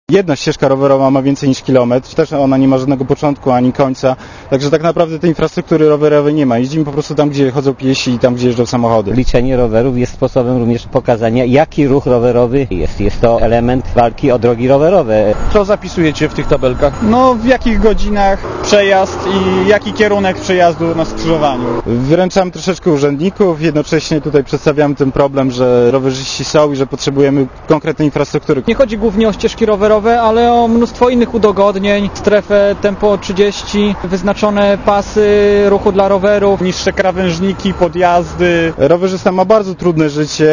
Posłuchaj, co mówią mieszkańcy Opola (151 KB)